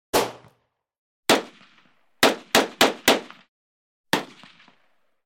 武器的声音 " 枪声001
描述：手枪枪声，通过在塑料砧板上敲击各种厨房用具，再加上砧板被紧紧撞击在一个大个子男人胸口的声音而产生的。
标签： 步枪 手枪 射击 一声枪响
声道立体声